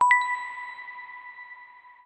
获得金币1.mp3